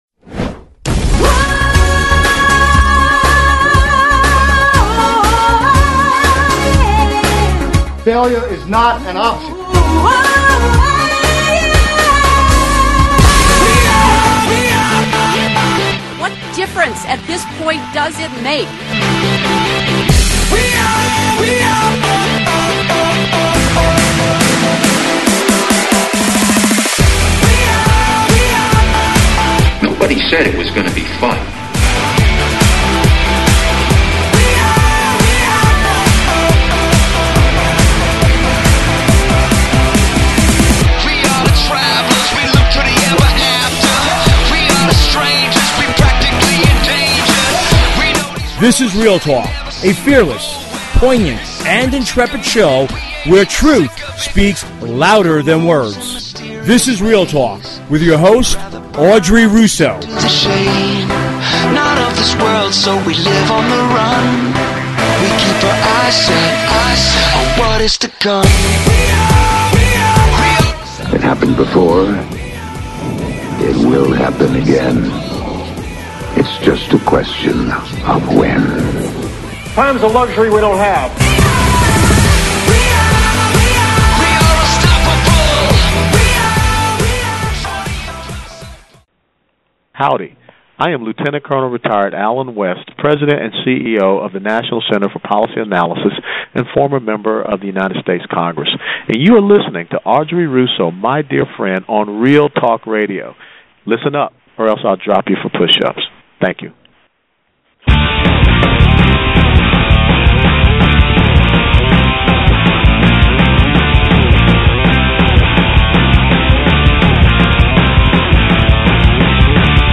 round table interview